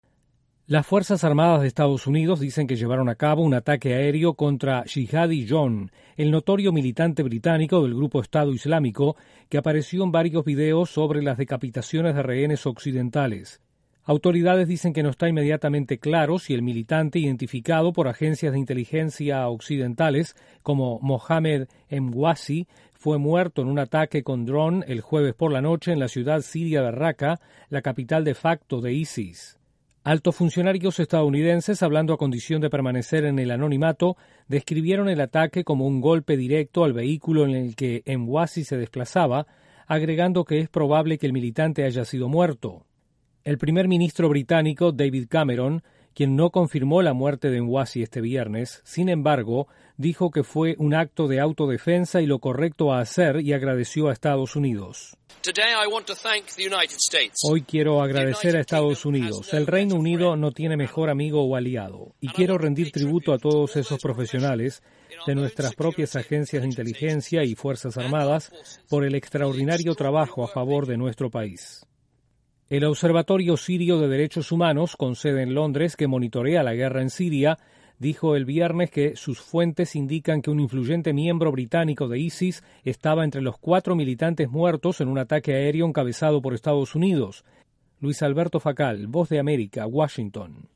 Un ataque aéreo de EE.UU. en Siria habría matado a un notorio militante británico de ISIS. Desde la Voz de América en Washington informa